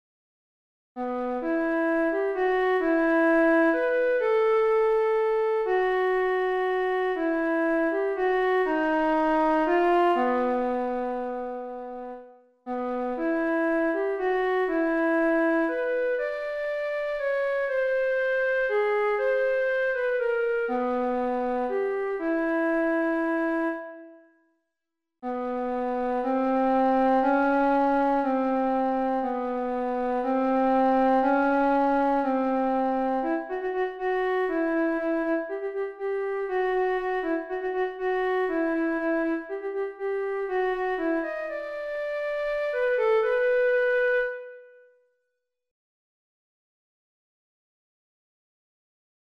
Filmthemen für Vorsingen (Alt) Audiodatei zum Üben